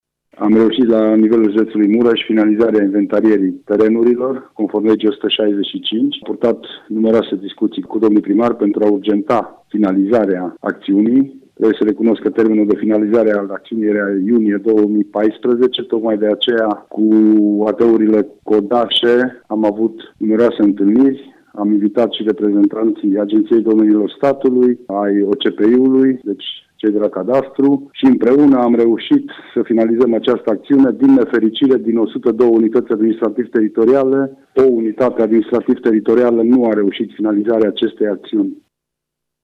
Prefectul judeţului Mureş, Lucian Goga, a declarat pentru RTM că, la 31 martie, 101 din cele 102 unităţi administrativ teritoriale au finalizat procedura, după mai multe întâlniri cu primarii reprezentanţii autorităţilor statului: